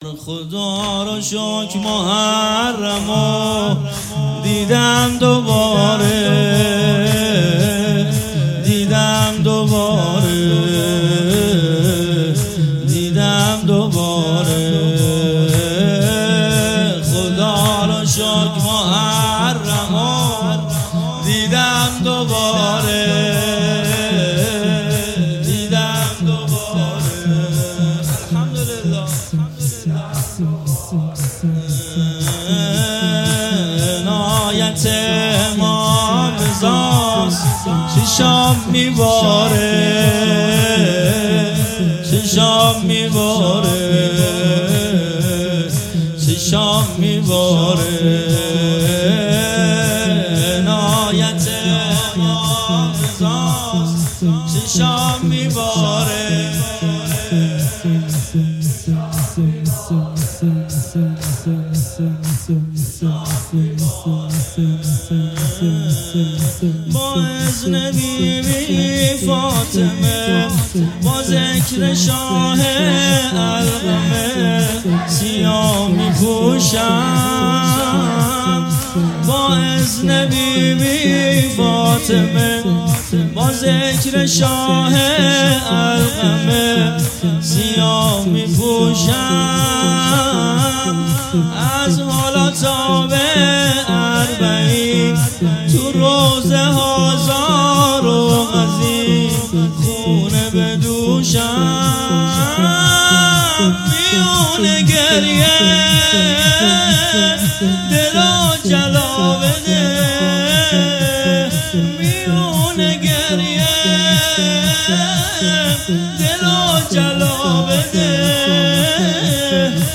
شب اول ، زمینه ، خداروشکر محرم رو دیدم دوباره
محرم الحرام ۱۴۴۵